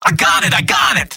Robot-filtered lines from MvM. This is an audio clip from the game Team Fortress 2 .
{{AudioTF2}} Category:Scout Robot audio responses You cannot overwrite this file.